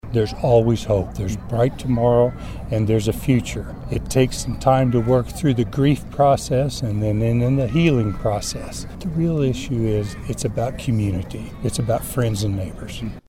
Mayor Bob Dixson believes the strength of Greensburg resolve shines through even today.
dixson-on-greensburg.mp3